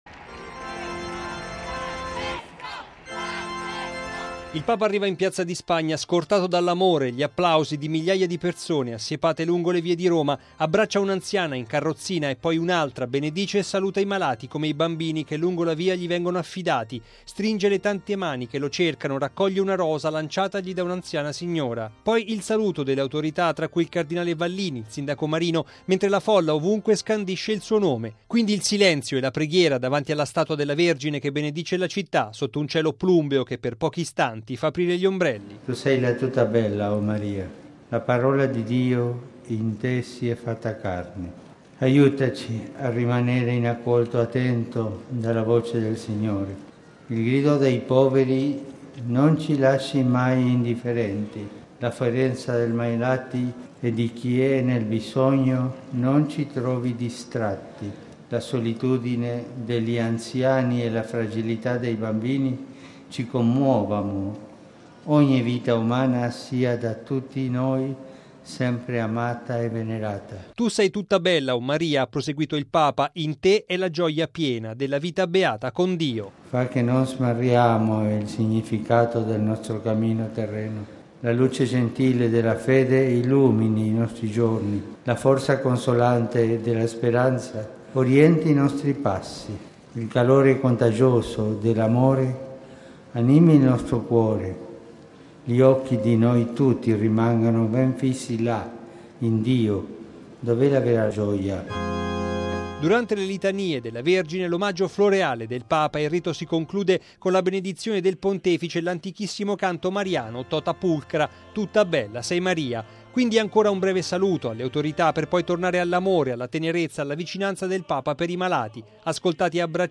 Il Papa arriva in Piazza di Spagna, scortato dall’amore e dagli applausi di migliaia di persone, assiepate lungo le vie della città.
Poi il saluto delle autorità tra cui il cardinale viacario Vallini, il sindaco Marino, mentre la folla da ogni parte scandisce il suo nome.